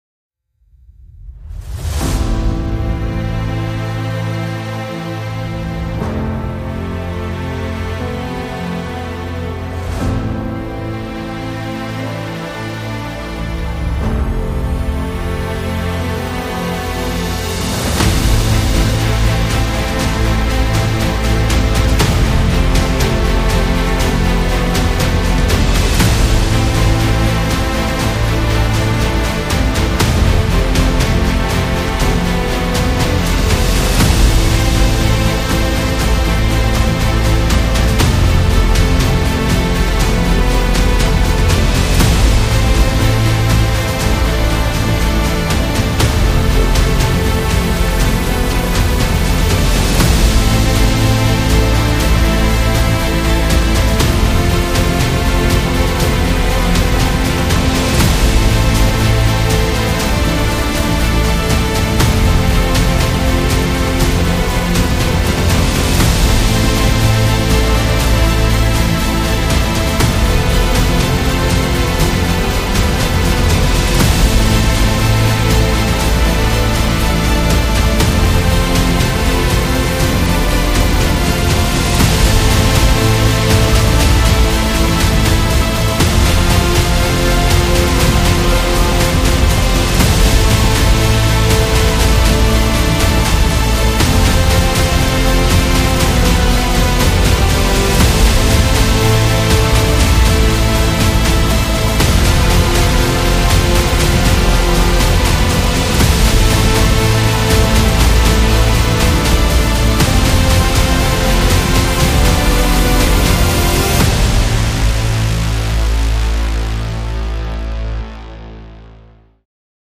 Cinematic